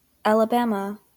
Alabama (/ˌæləˈbæmə/
AL-ə-BAM)[8] is a state in the Southeastern and Deep Southern regions of the United States.